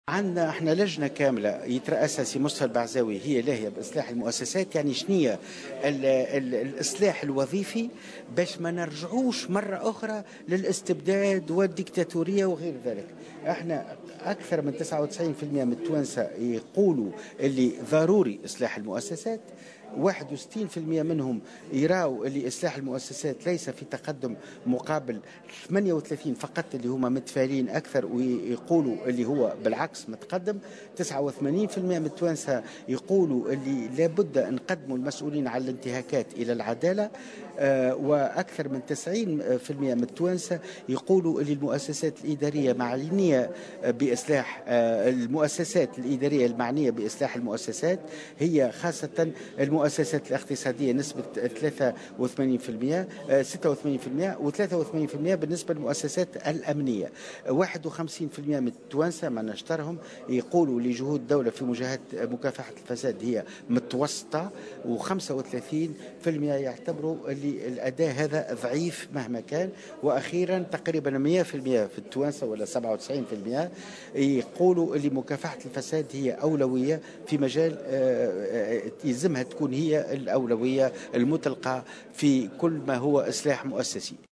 خلال ندوة صحفية انعقدت اليوم الجمعة 31 جويلية 2015